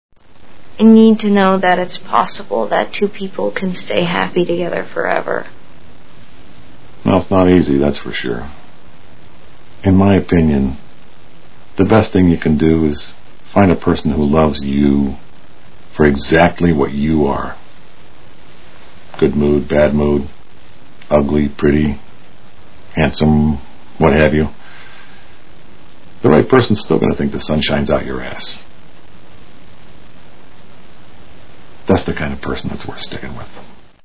Juno Movie Sound Bites